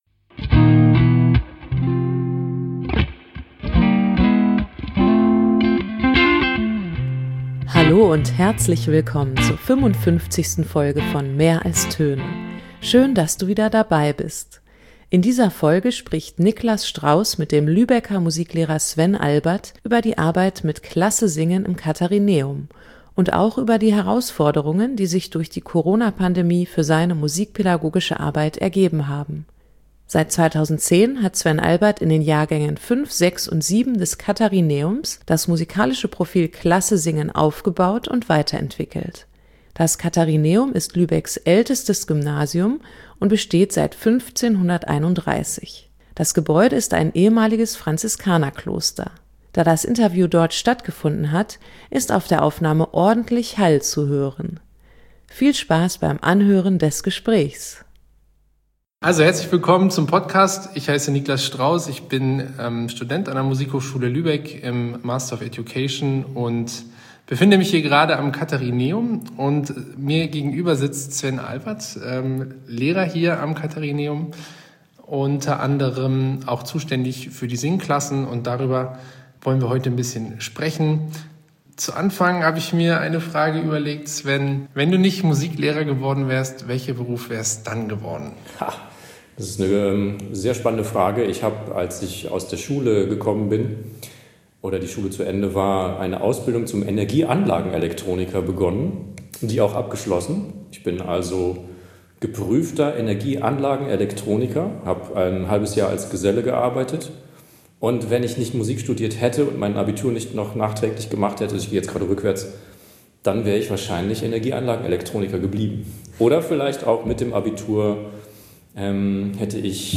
Im Gespräch geht es um folgende Fragen: In welchen Momenten wird deutlich, dass der „Beruf Musiklehrer“ die richtige Wahl war? Was macht „Klasse! Singen!“ aus? Was zeichnet eine „gute“ musikalische Leitungspersönlichkeit aus?